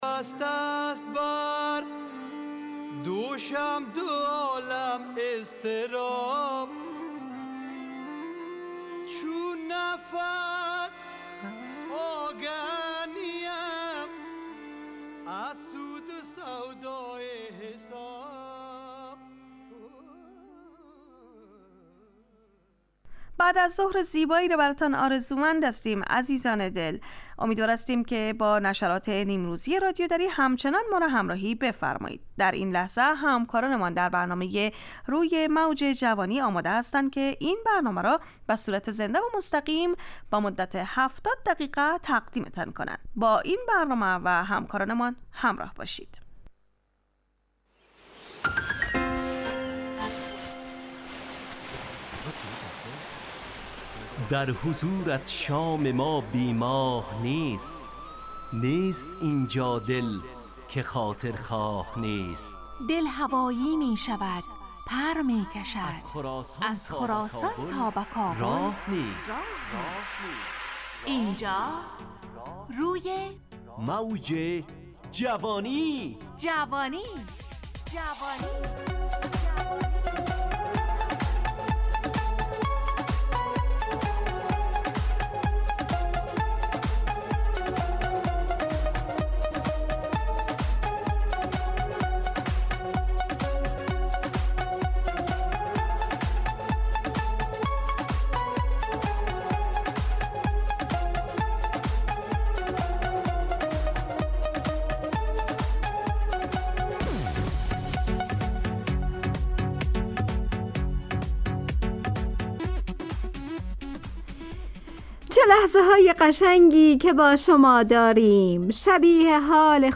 همراه با ترانه و موسیقی مدت برنامه 70 دقیقه . بحث محوری این هفته (دعا)